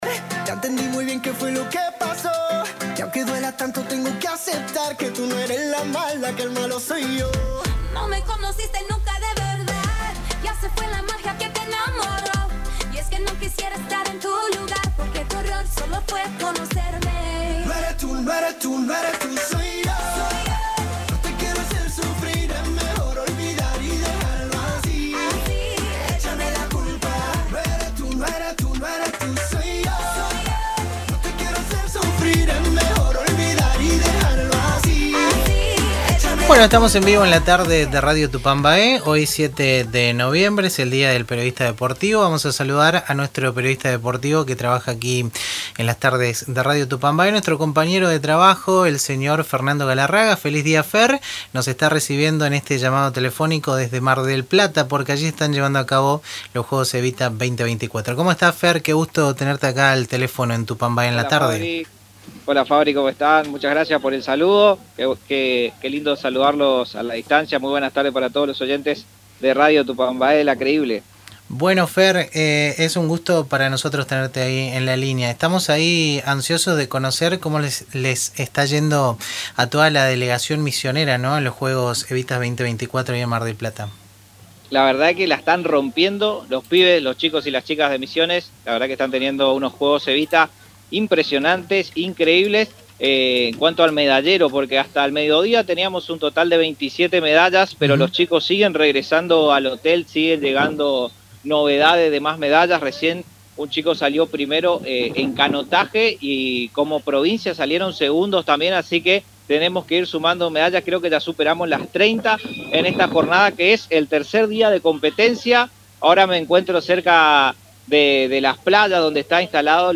En diálogo con Radio Tupa Mbae